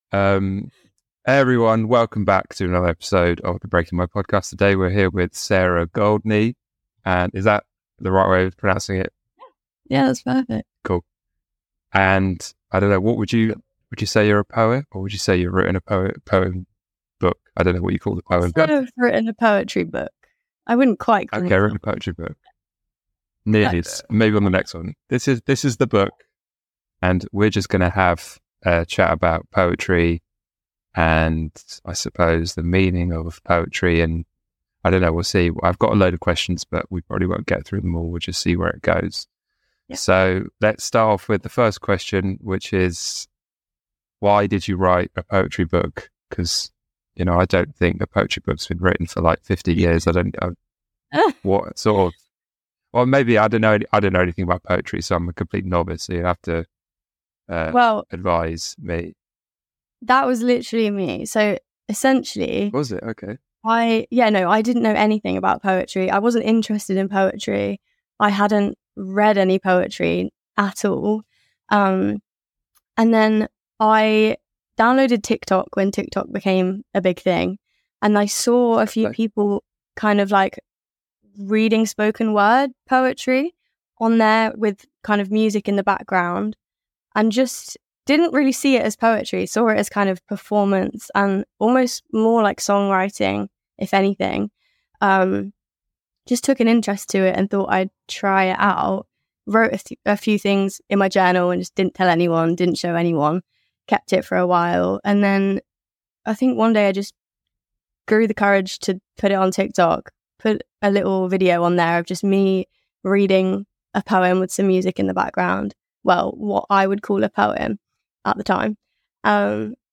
We spoke about her poetic and creative process, the significance of poetry and how writing can be a therapeutic and meditative practice. It was a refreshing and eye-opening conversation, I hope you enjoy it!Support the showThank you for listening, be sure to follow and leave a review ...